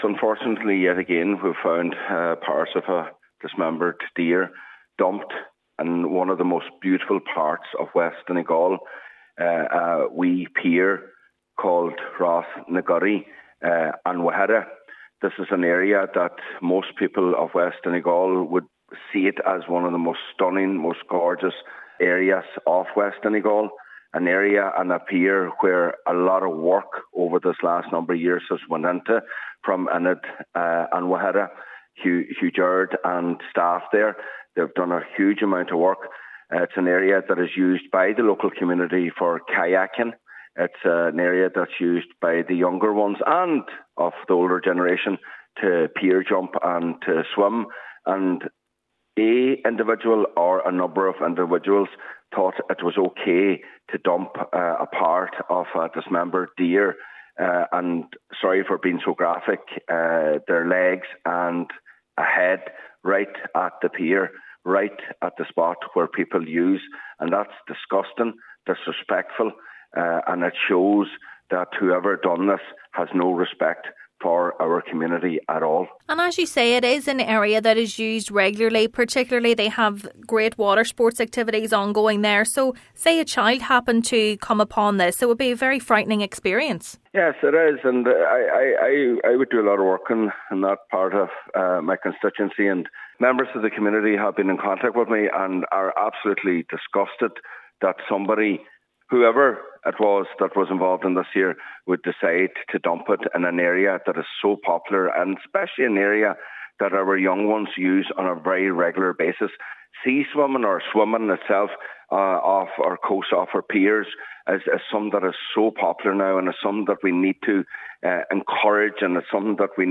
Councillor Micheal Choilm MacGiolla Easbuig has hit out at those responsible.